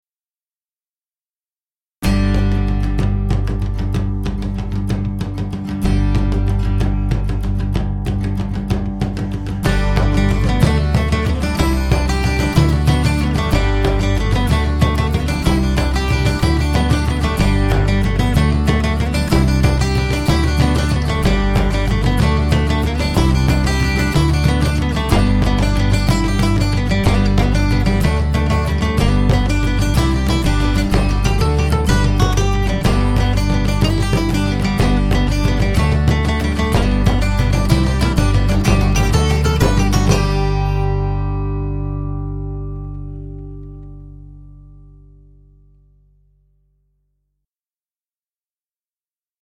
(trad.)
vocal, bodhran
guitar
guitar, bass